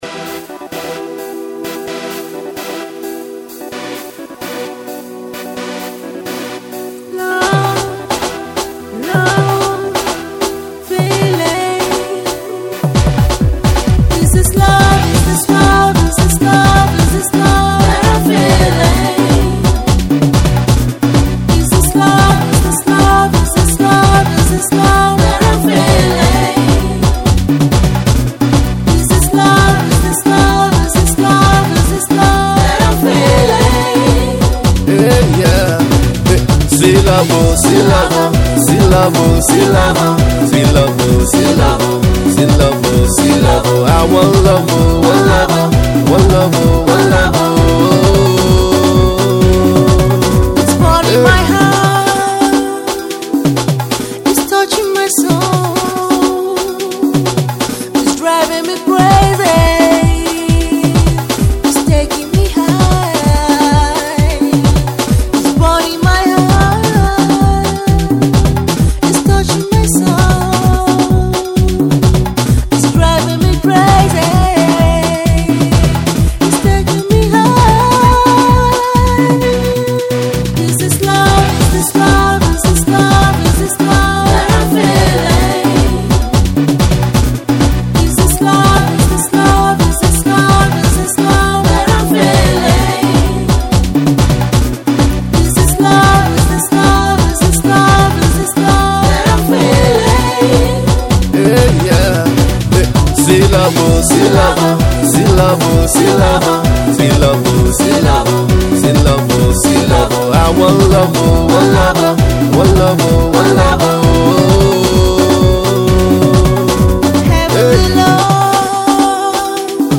love single